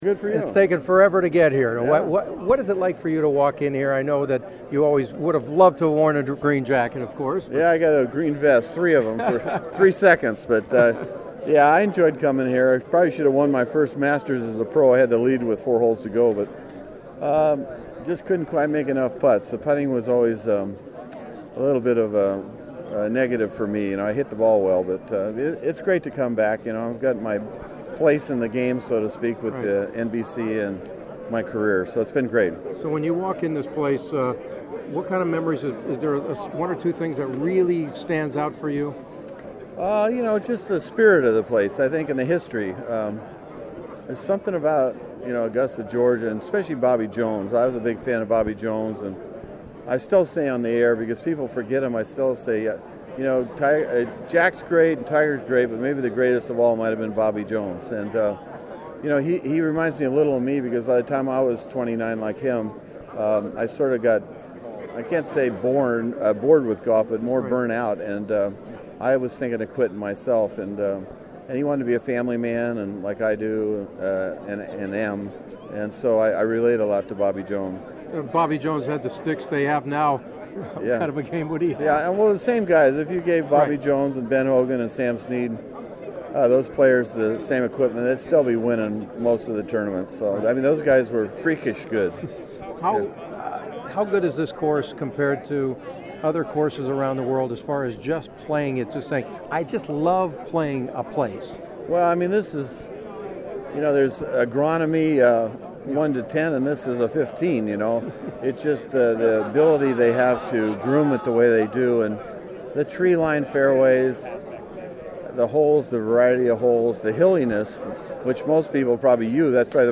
The following are some of my Masters preview interviews leading into Thursday’s first round.
on Wednesday at the Masters